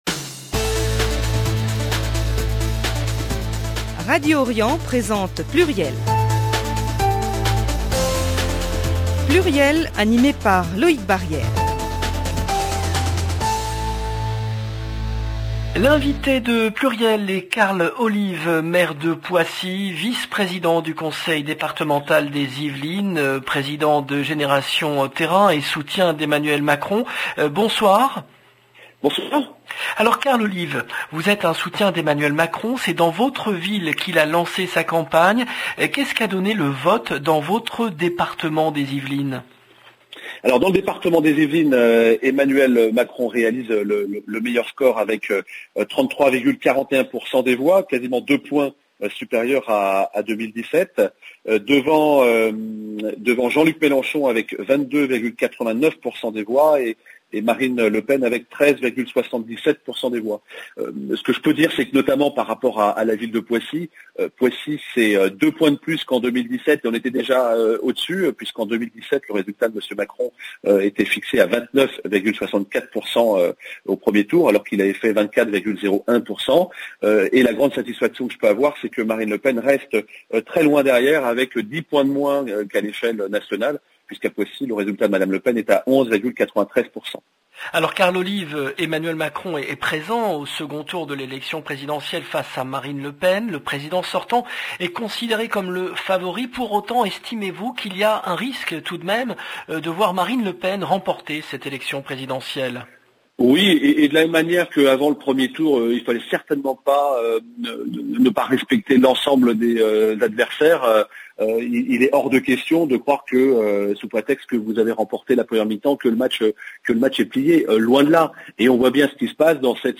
Poissy LB PLURIEL, le rendez-vous politique du vendredi 15 avril 2022 0:00 19 min 18 sec 15 avril 2022 - 19 min 18 sec Karl Olive, maire de Poissy L’invité de PLURIEL est Karl Olive, maire de Poissy, vice-président du Conseil départemental des Yvelines, Président de Génération Terrain, soutien d’Emmanuel Macron.